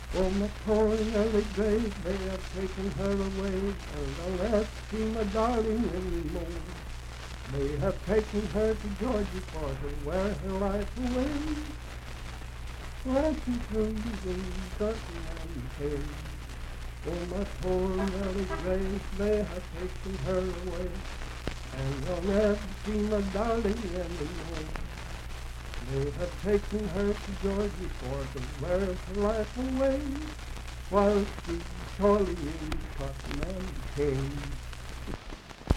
Unaccompanied vocal music
Love and Lovers, Minstrel, Blackface, and African-American Songs
Voice (sung)
Grant County (W. Va.)